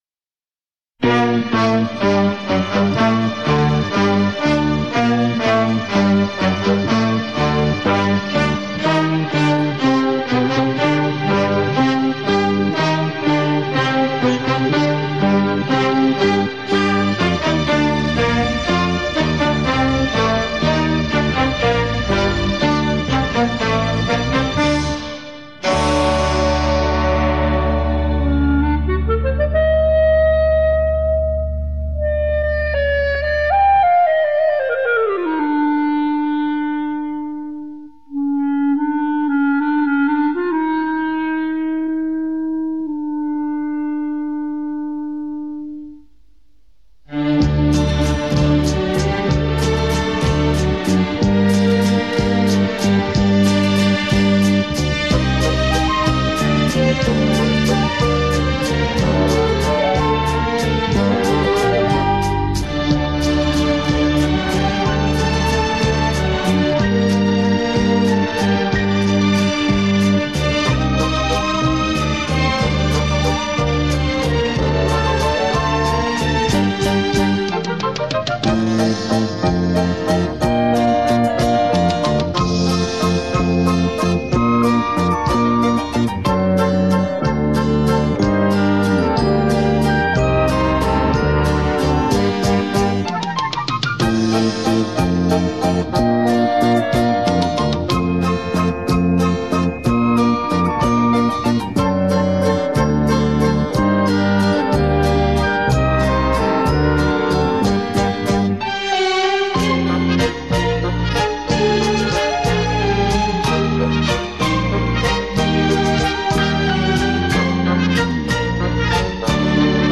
(快三步)